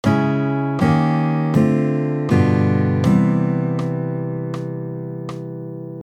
Then, add the bVII-7 degree of Eb7 to prepare for the Eb7 = Db-7 -> Eb7 -> Dm7 -> G7 -> CMaj7.